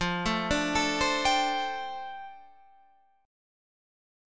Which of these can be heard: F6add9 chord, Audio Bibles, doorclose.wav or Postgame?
F6add9 chord